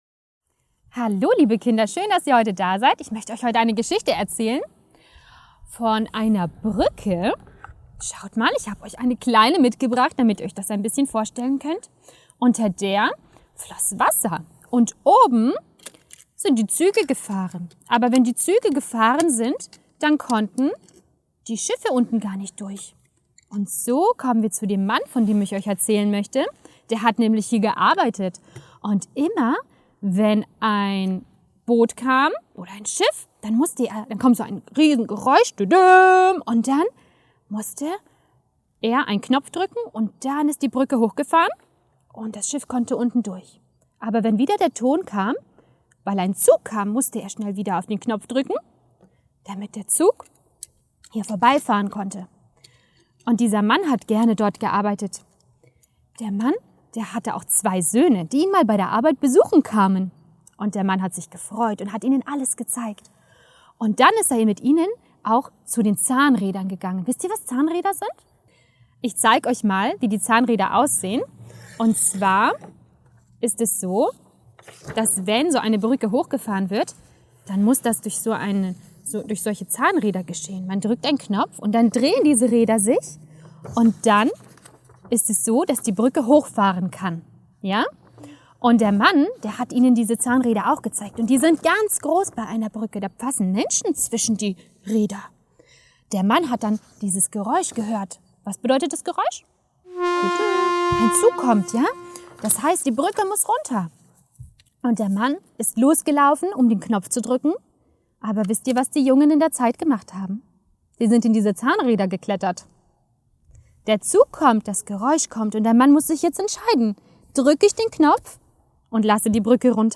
Wahre Kurzgeschichten für Kinder